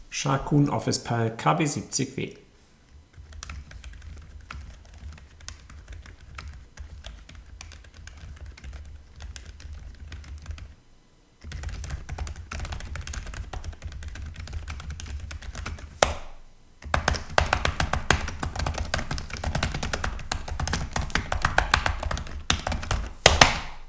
Der dumpfeste Taster von allen und damit der bei normaler Betätigung dezenteste ist von Sharkoon, wenngleich um den Preis recht schwammiger Akustik.
Grundsätzlich bleibt die KB70W tatsächlich sehr leise und über alle Tasten hinweg recht homogen. Was immer auch beim vorsichtigen Tippen hörbar bleibt, ist wie bei vielen Silent-Switches das Ticken beim Antippen der Tastenkappen.
• Sehr leises Tippen